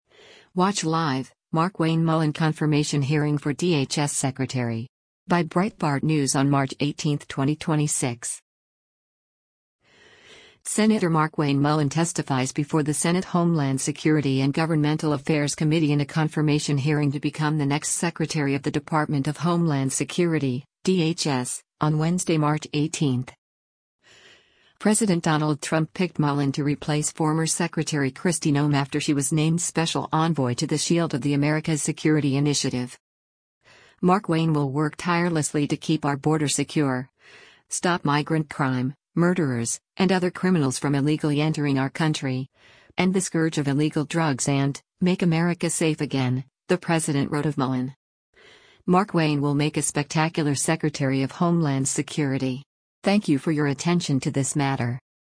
Sen. Markwayne Mullin testifies before the Senate Homeland Security & Governmental Affairs committee in a confirmation hearing to become the next Secretary of the Department of Homeland Security (DHS) on Wednesday, March 18.